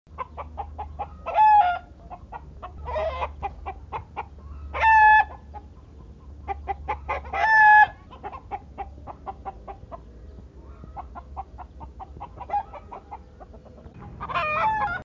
Download Chicken sound effect for free.
Chicken